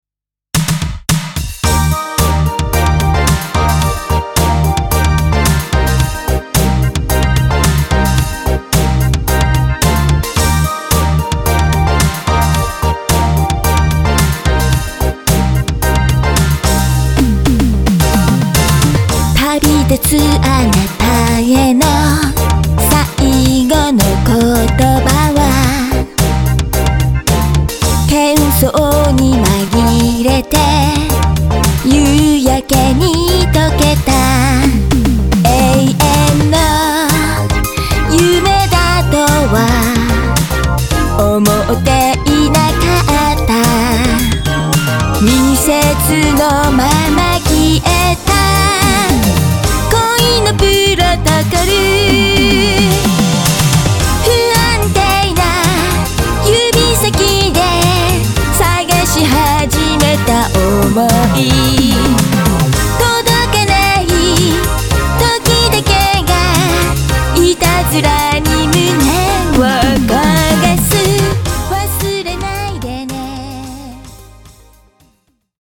８０年代の歌謡曲的アプローチのシティポップ